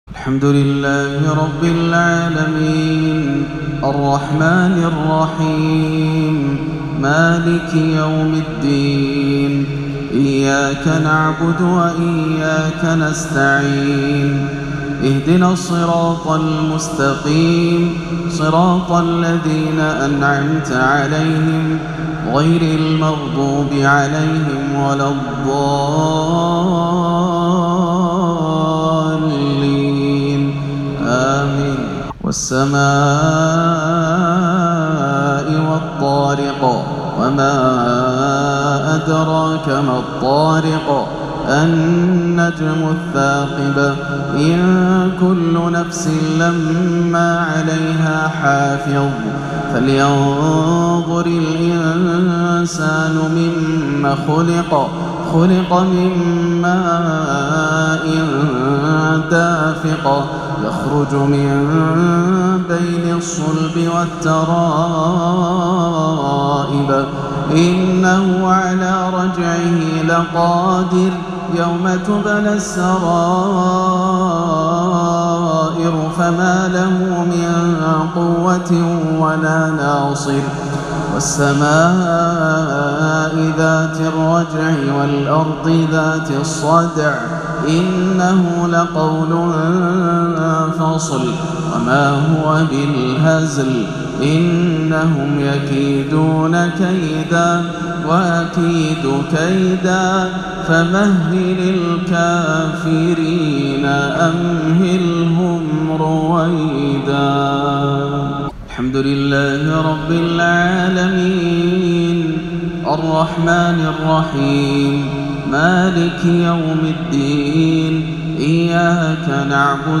ترتيل تدبري لسورتي الطارق والعاديات - مغرب 8-2-1439 > عام 1439 > الفروض - تلاوات ياسر الدوسري